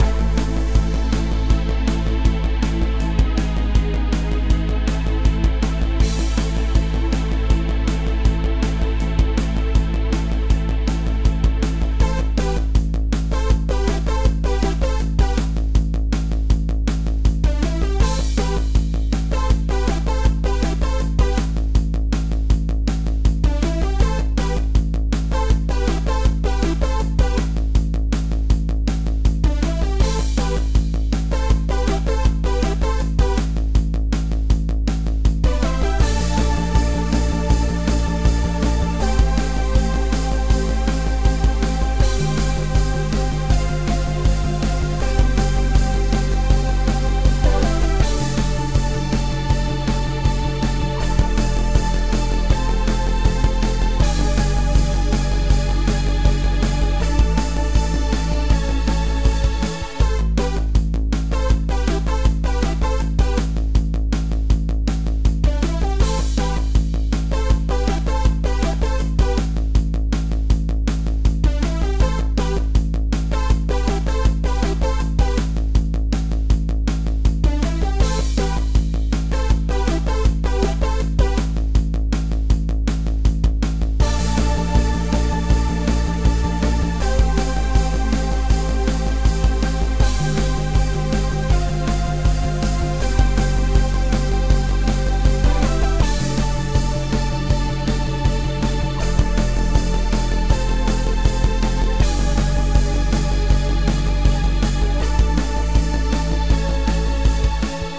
Retrowavee 80 Synth game music
Retrowave game music